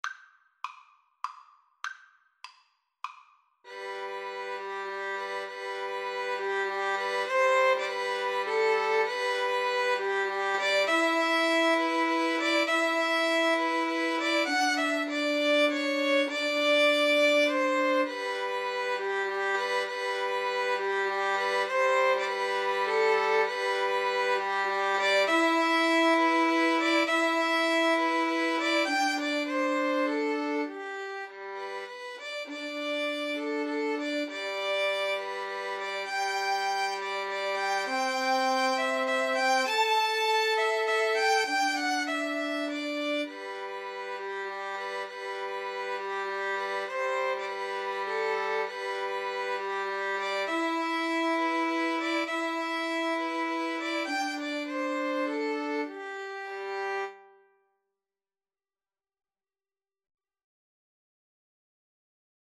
It is written in a simple waltz style.
Moderato
Violin Trio  (View more Easy Violin Trio Music)
brahms_waltz_3VLN_kar2.mp3